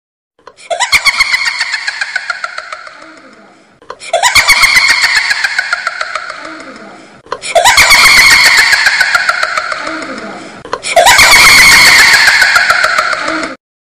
Creepy Little Girl Laughing “Hahaha”